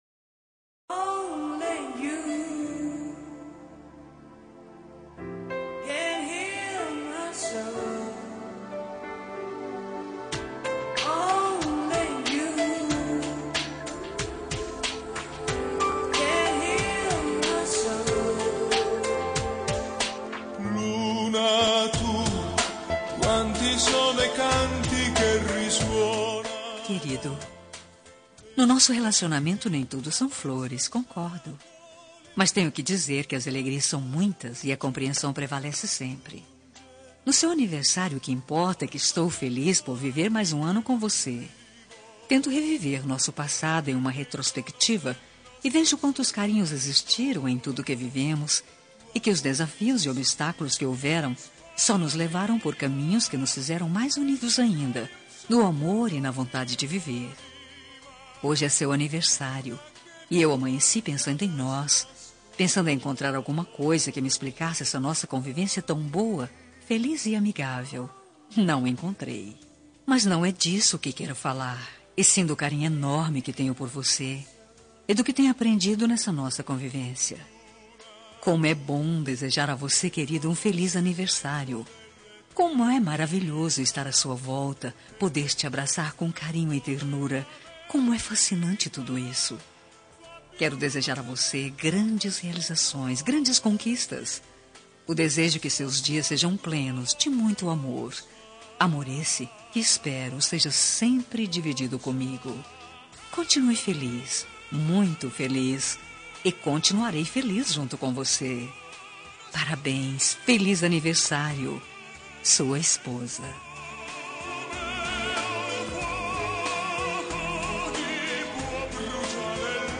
Telemensagem de Aniversário de Marido – Voz Feminina – Cód: 1156